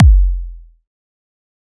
EDM Kick 7.wav